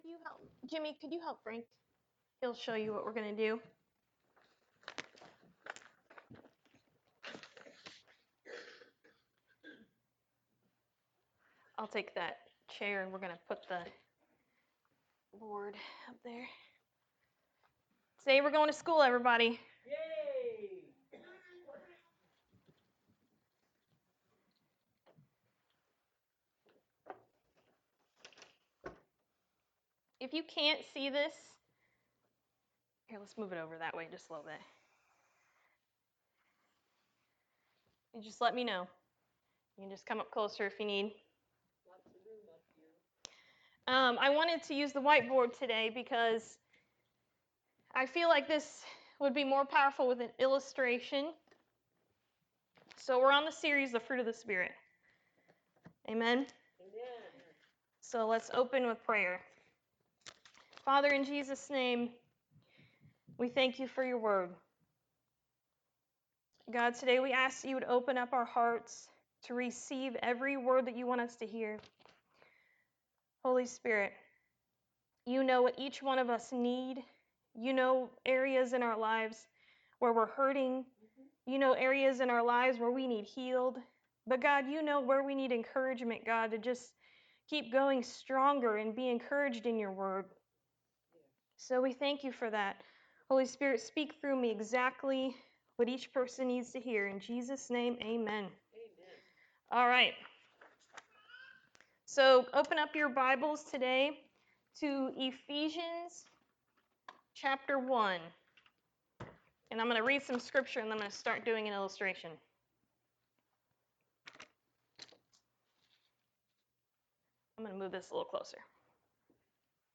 Romans 5:11-14 Service Type: Sunday Morning Service What is holding you back from loving others?